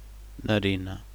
Captions English Add a one-line explanation of what this file represents Portuguese Pronúncia da palavra "Narina"
Narina_sound.wav